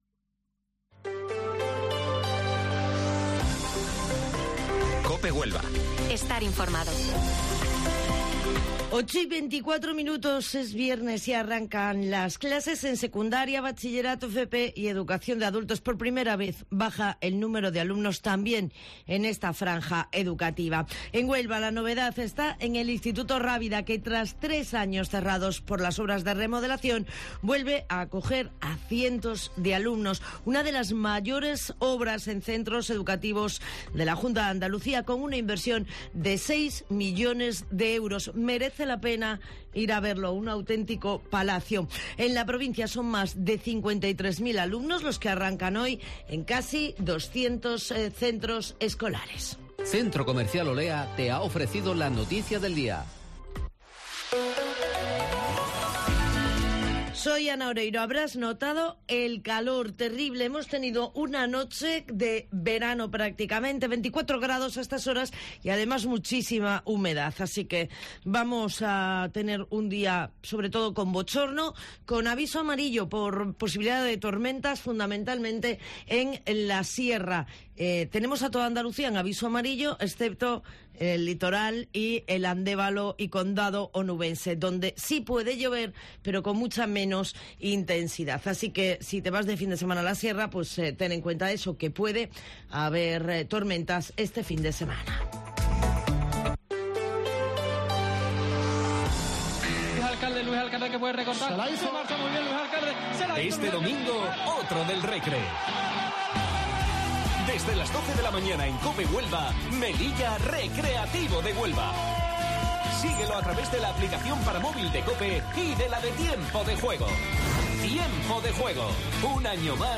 Informativo Matinal Herrera en COPE 15 de septiembre